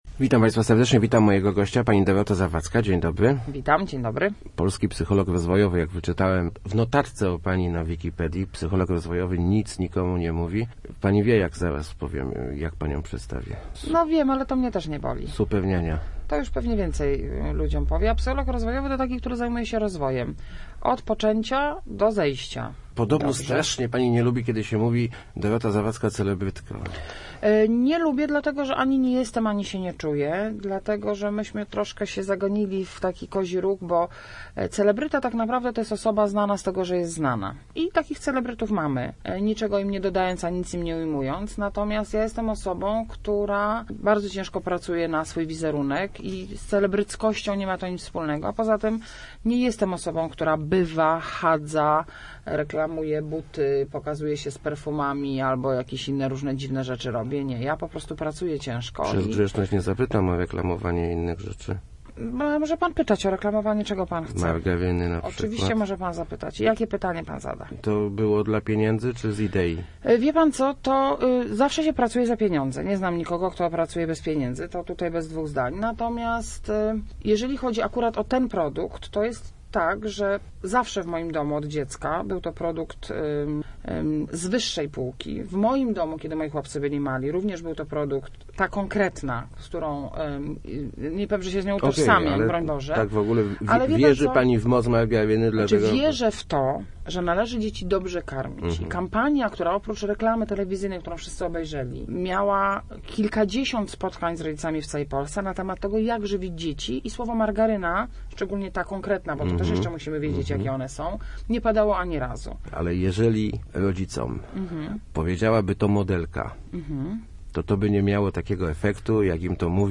Sześciolatki są gotowe, by pójść do szkoły – przekonywała w Rozmowach Elki psycholog rozwojowy Dorota Zawadzka. Popularna z telewizji Superniania zaprzeczała przy tym, że jest głosem rządu w tej sprawie.